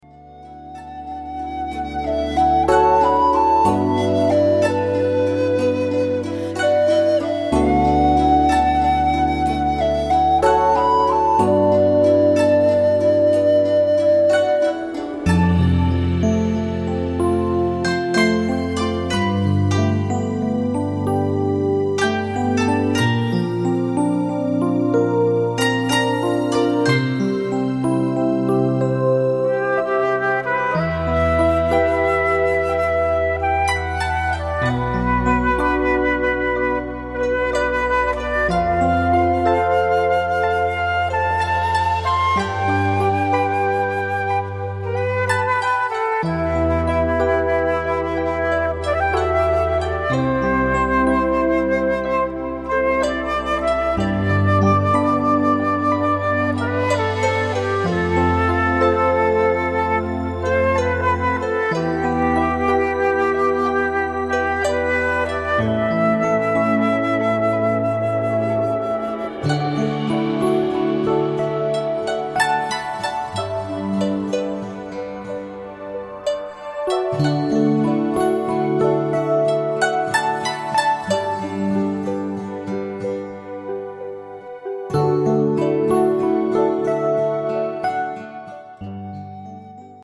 Im Rhythmus des Atems ist auch die Musik komponiert.